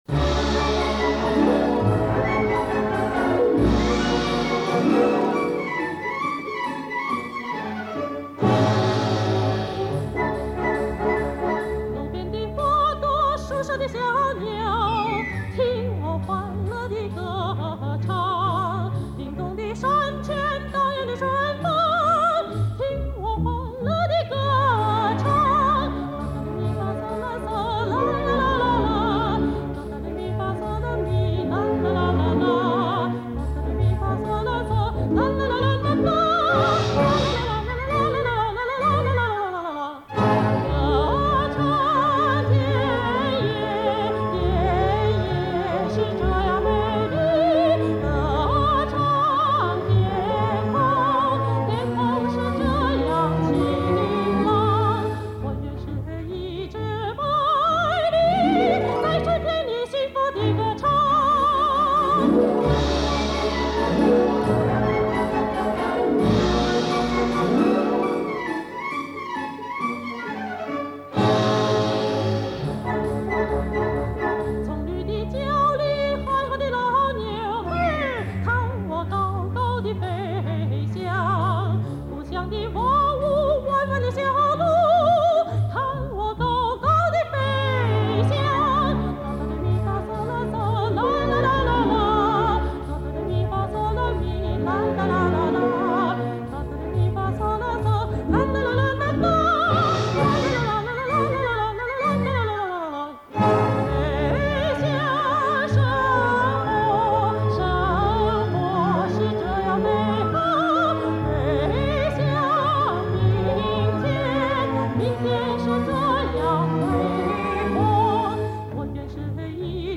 她苦心学习戏曲和民歌，摸索出一套适合自己特点的中西结合的唱法。